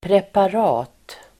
Uttal: [prepar'a:t]